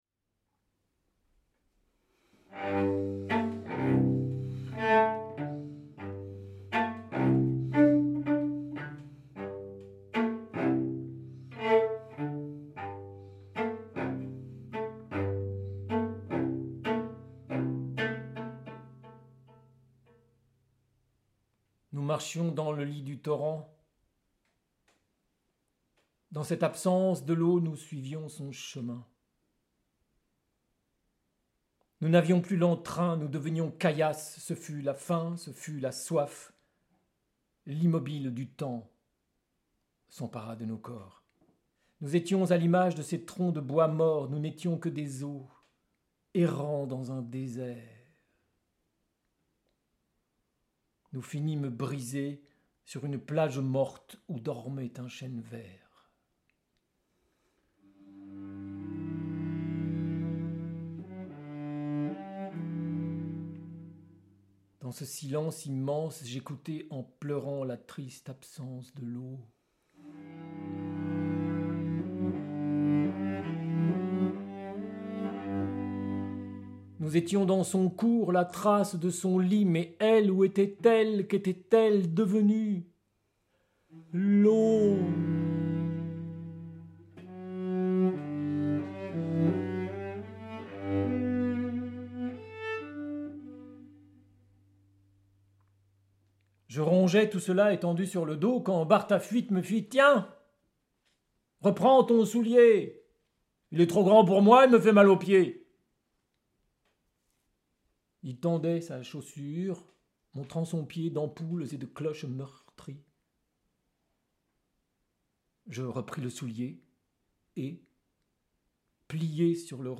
accompagné de son violoncelle